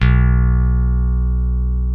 B1 2 F.BASS.wav